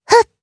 Lewsia_A-Vox_Casting1_jp.wav